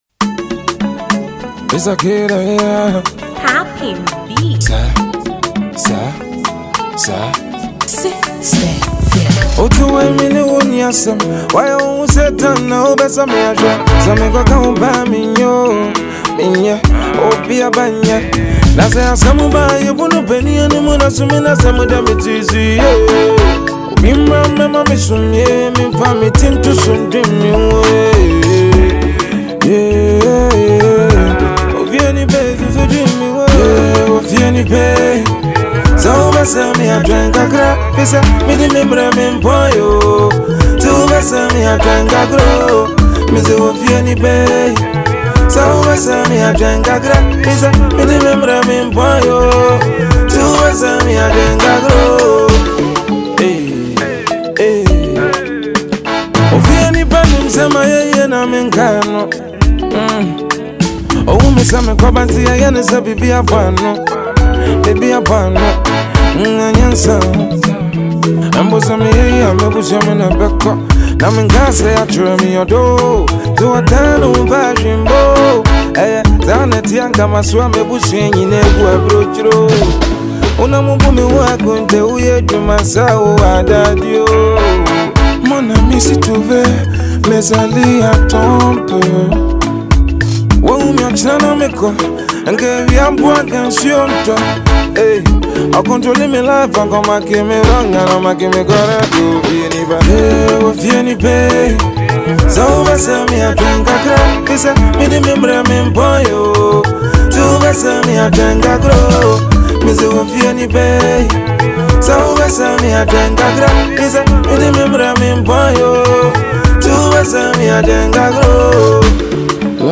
hana hiplife star